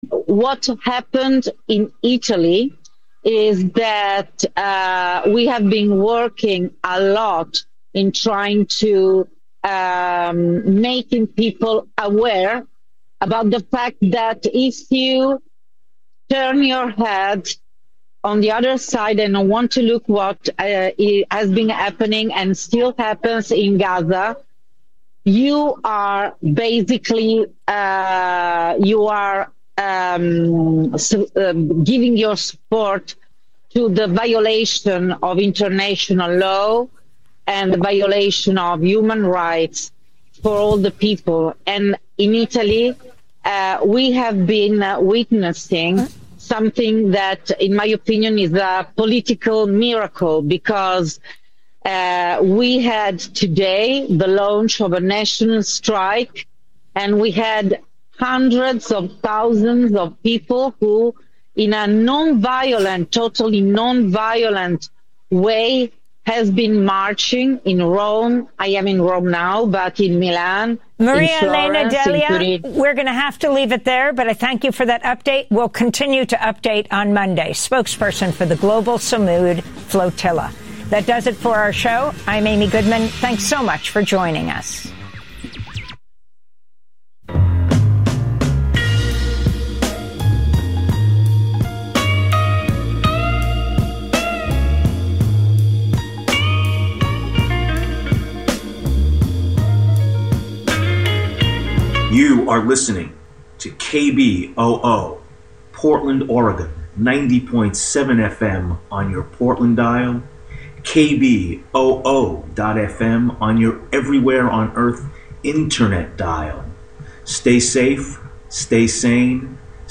Evening News on 10/03/25
Hosted by: KBOO News Team